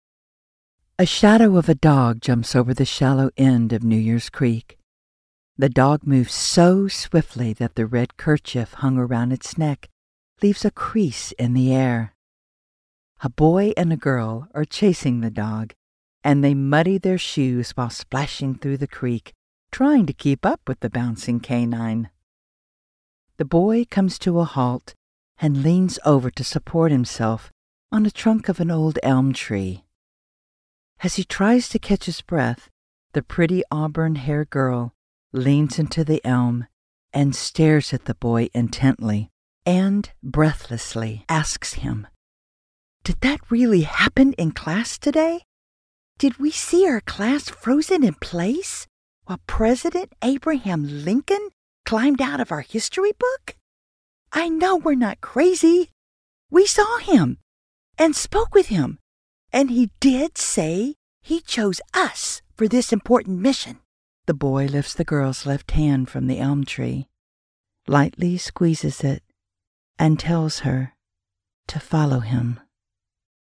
sample audiobook read
English - USA and Canada